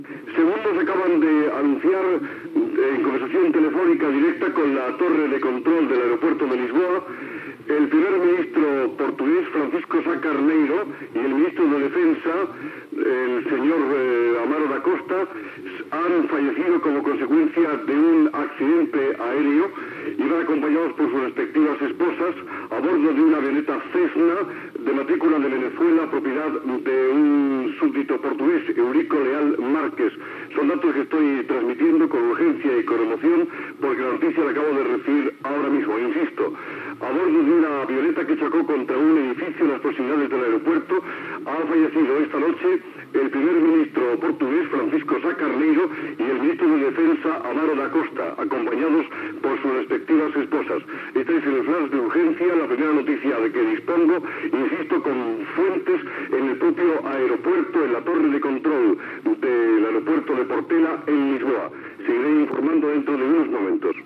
Connexió amb Lisboa, per informar de l'accident aeri, a prop de l'aeroport de Lisboa, en el qual ha mort el primer ministre portugués Francisco Sá Carneiro i altres persones que l'acompanyaven
Informatiu